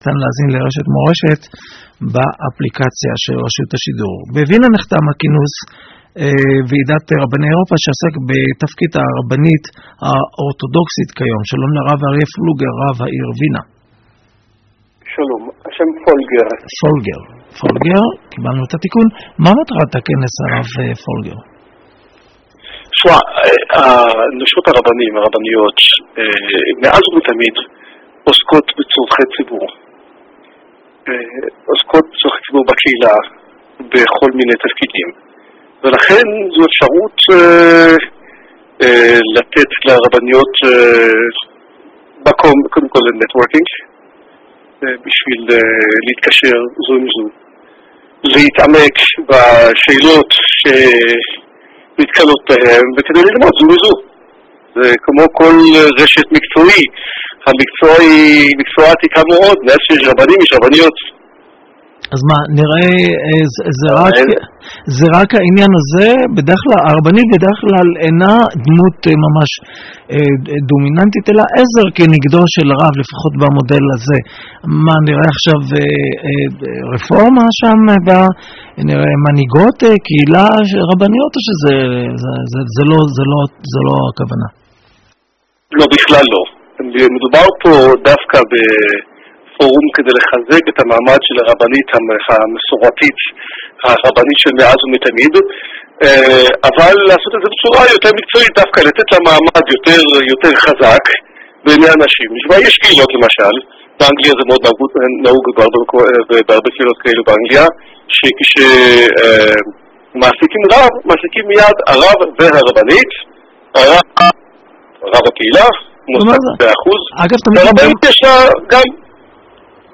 What is Modern Orthodoxy (from a radio segment)